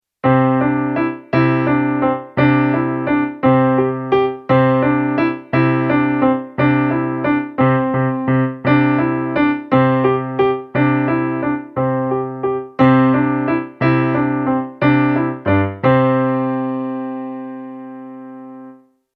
初めて４分の３拍子が出てきました。
楽譜的には、アクセント記号（強調する）が出てきました。
それから、強めに（f）と弱めに（p）も出てきました。